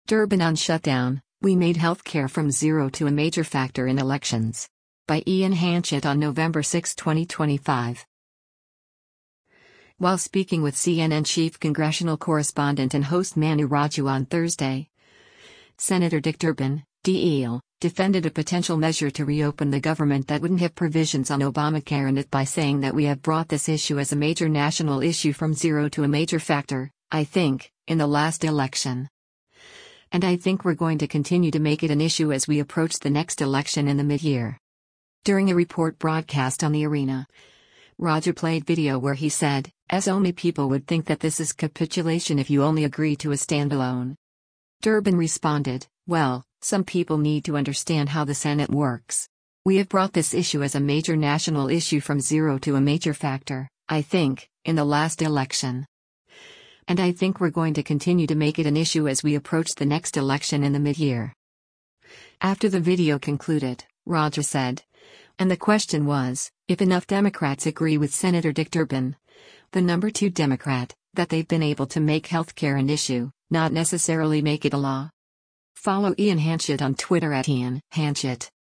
During a report broadcast on “The Arena,” Raju played video where he said, “[S]ome people would think that this is capitulation if you only agree to a standalone.”